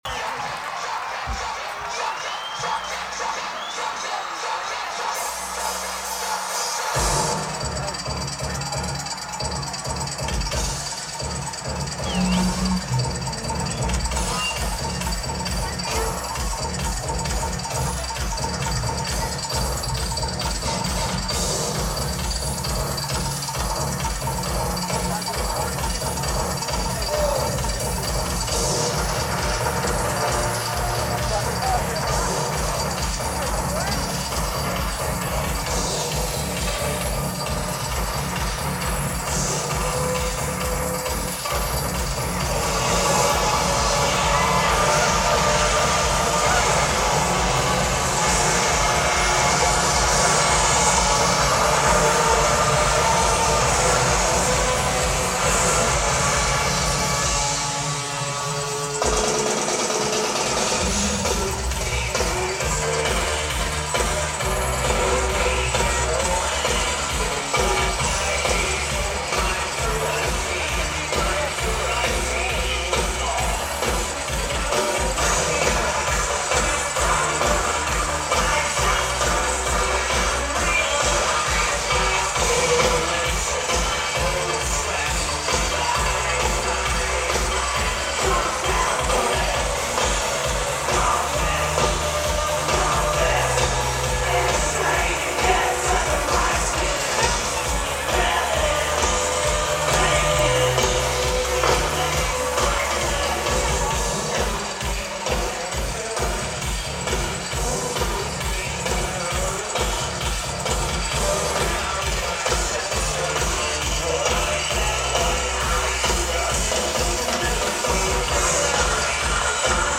Devore Stadium
Lineage: Audio - AUD (Sony WM-D3)